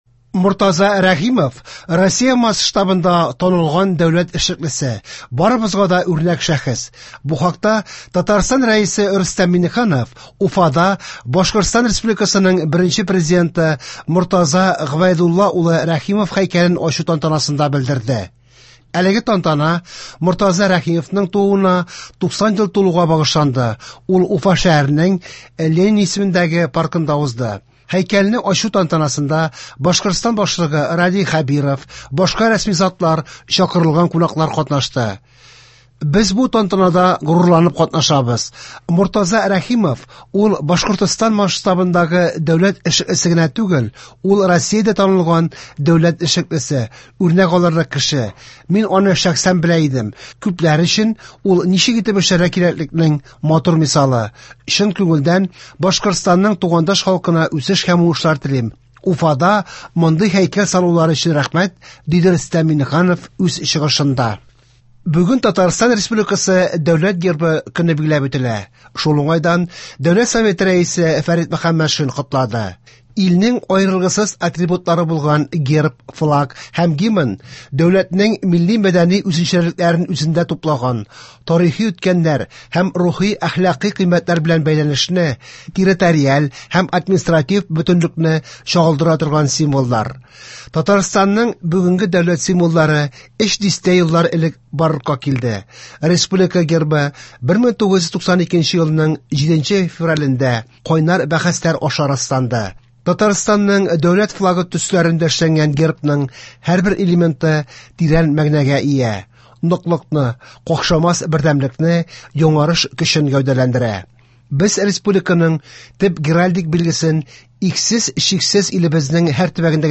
Яңалыклар (7.02.24)